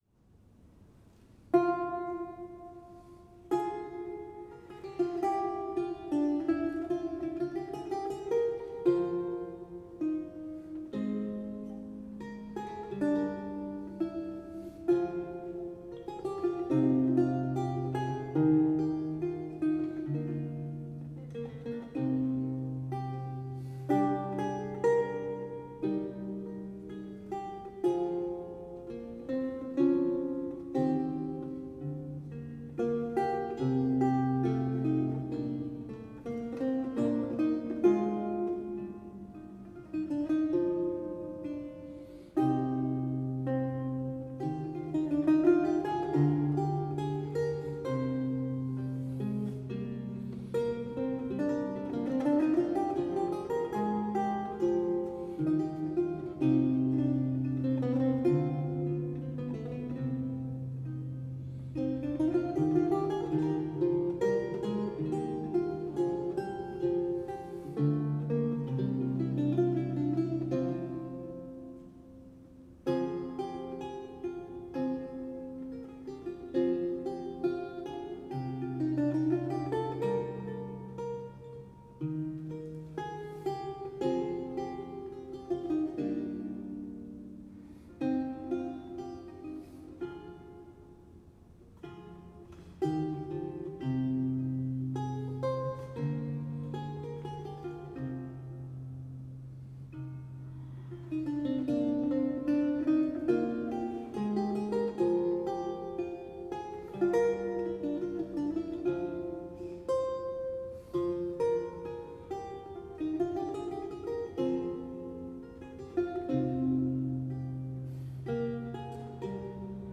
Audio recording of a lute piece
a 16th century lute music piece originally notated in lute tablature